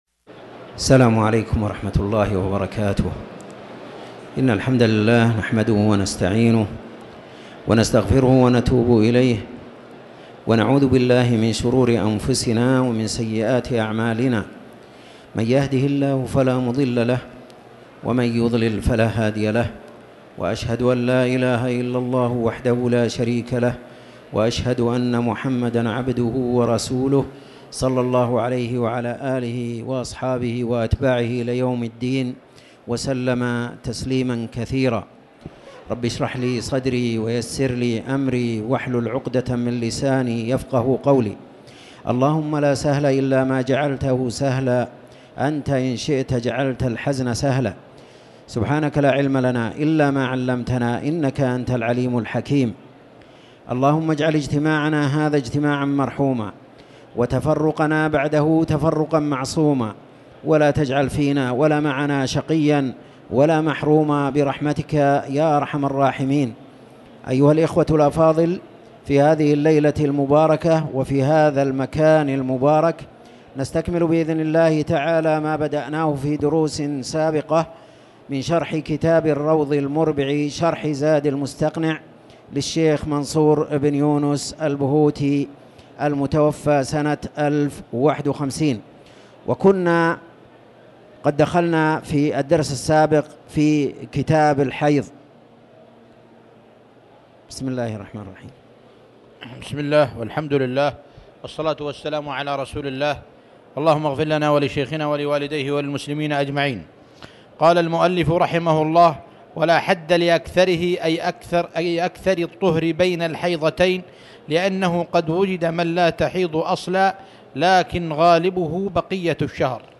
تاريخ النشر ٢٥ رجب ١٤٤٠ هـ المكان: المسجد الحرام الشيخ